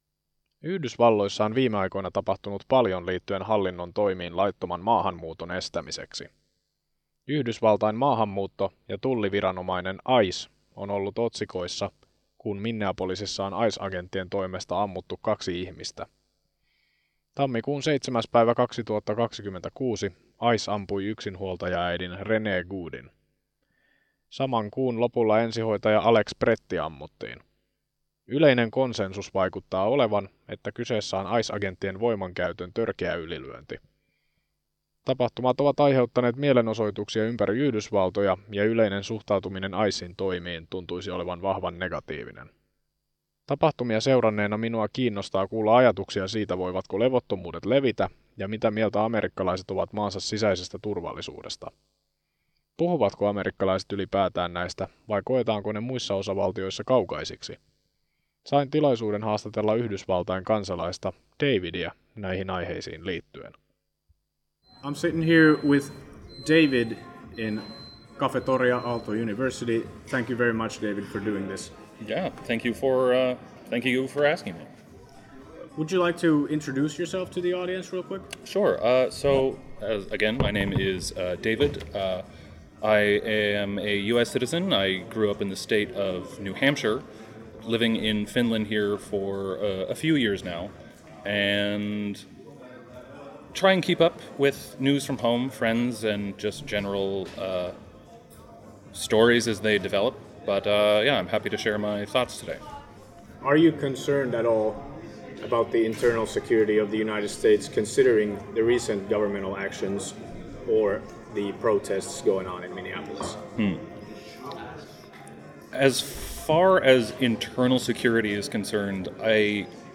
Uutisjuttu: Yhdysvaltain kansalaisen ajatuksia liittyen Minneapolisin tapahtumiin